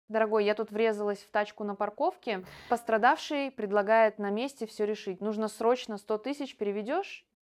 1. Нерегулярное дыхание
В фейковых записях дыхание может полностью отсутствовать или звучать неестественно — например, появляться посреди слова.